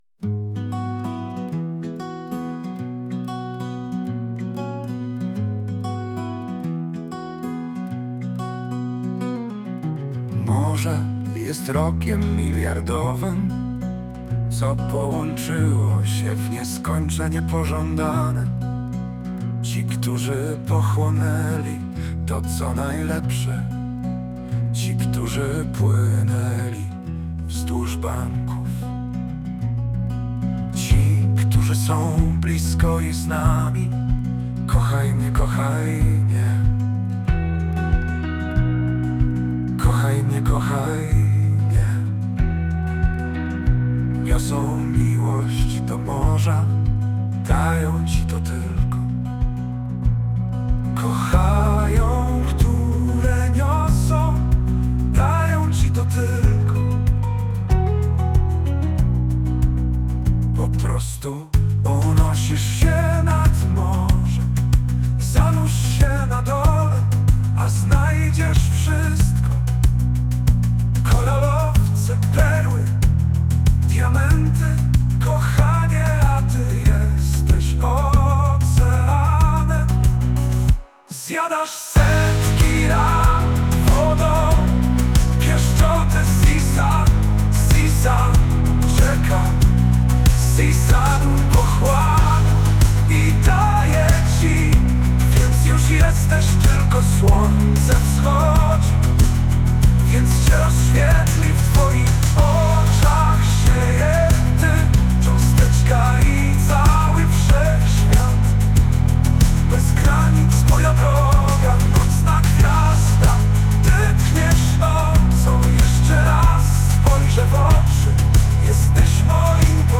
СТИЛЬОВІ ЖАНРИ: Ліричний
Незвичайно гарно...музична композиція просто супер! 16 hi 31